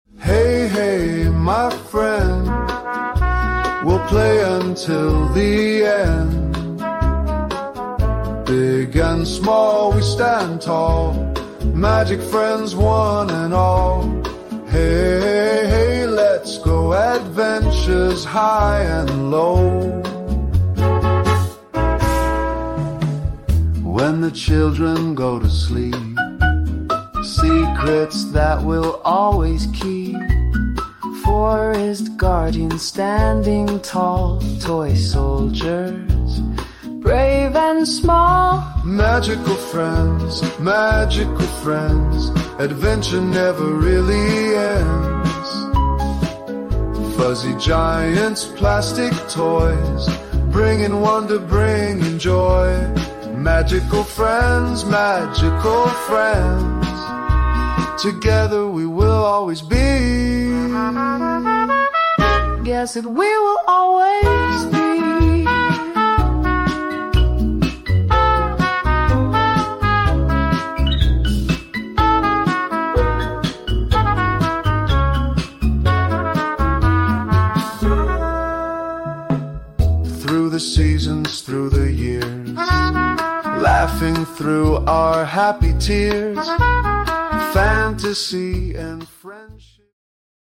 mashup
friendship song